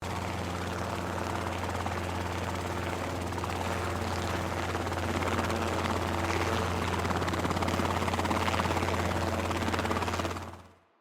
Helicopter Fly by
SFX
yt_KNtpdQbubRo_helicopter_fly_by.mp3